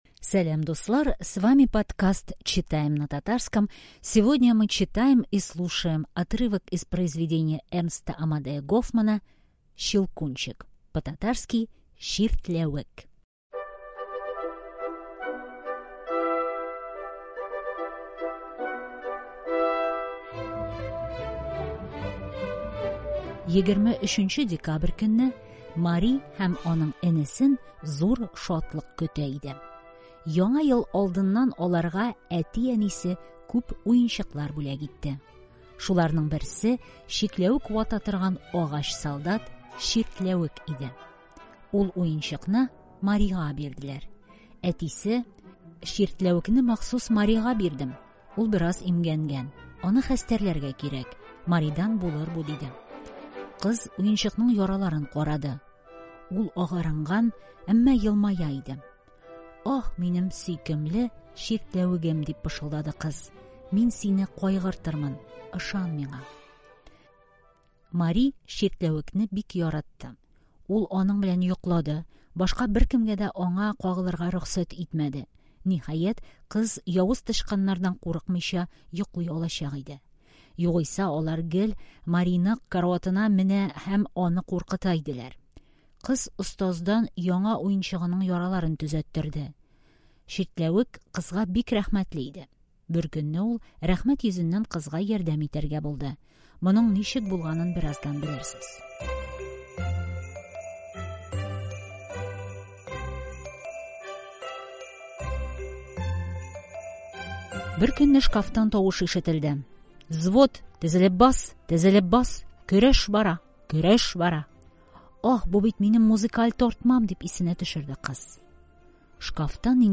Прилагаем красивую аудиосказку, перевод ключевых фраз и тест.